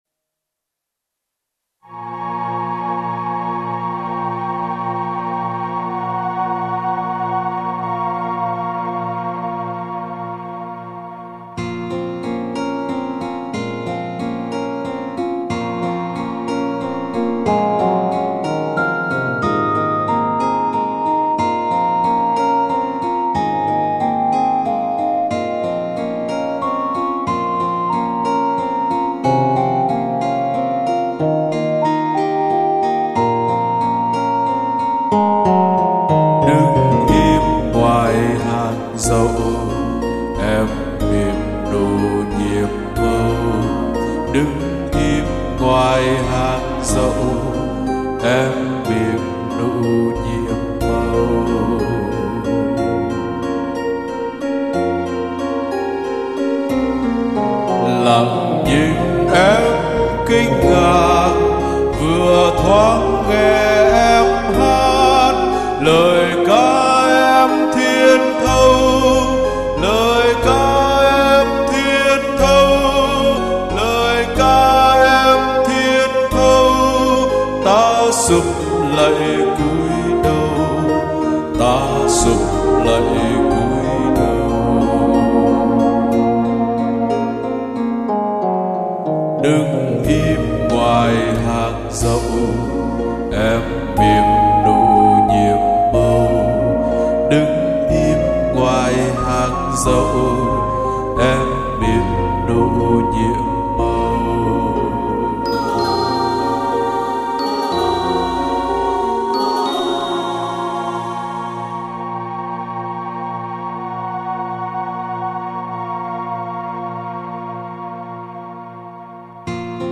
11/02/2016 in Âm Nhạc